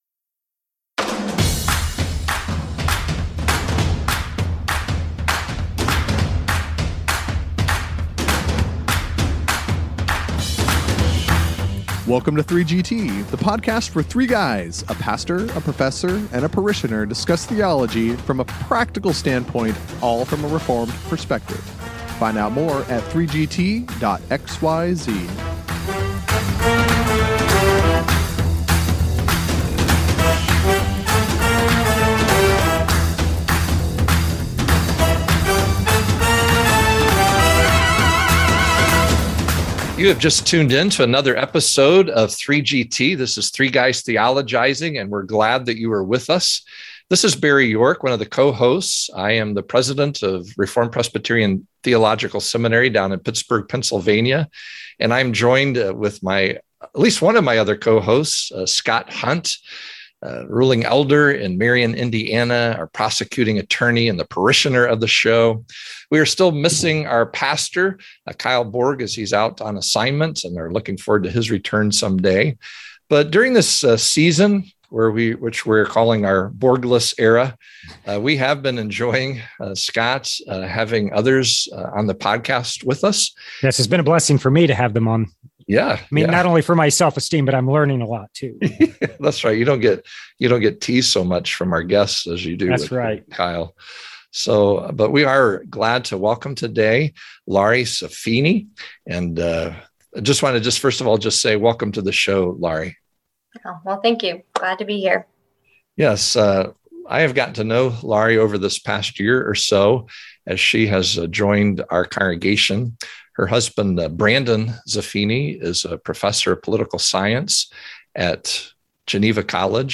Still holding at 3GT minus one, the parishioner and professor invite another guest on this latest episode.